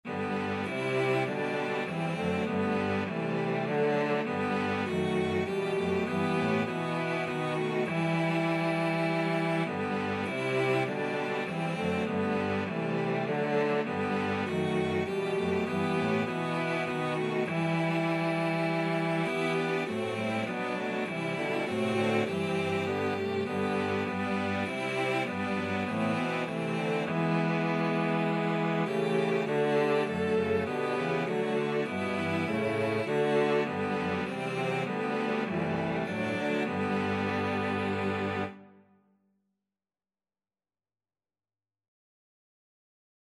4/4 (View more 4/4 Music)
Cello Quartet  (View more Easy Cello Quartet Music)
Classical (View more Classical Cello Quartet Music)